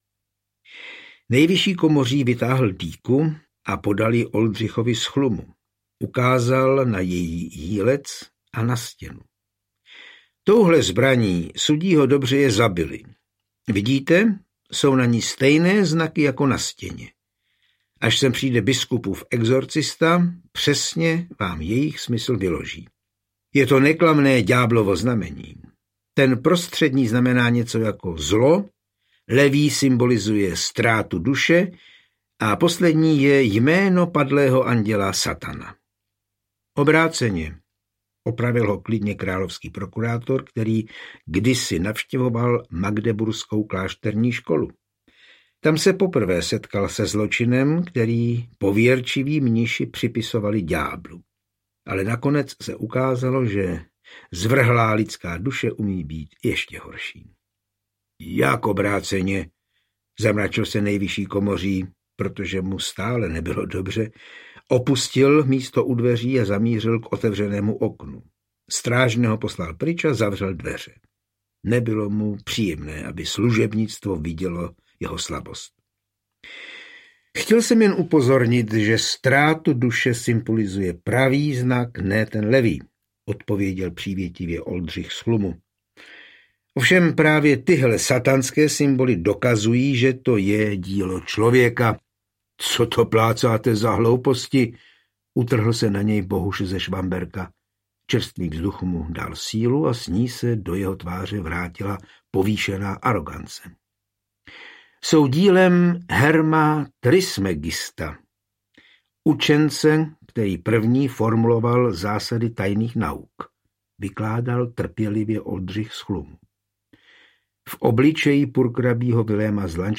Oldřich z Chlumu - Pražské zločiny audiokniha
Speciální edice na přání posluchačů, bez hudebních předělů a podkresů.
Ukázka z knihy